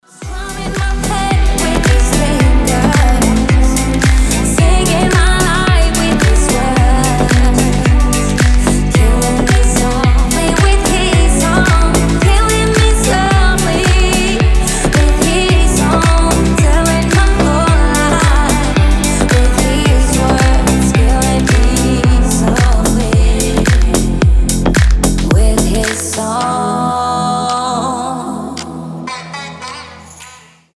• Качество: 320, Stereo
deep house
retromix
Cover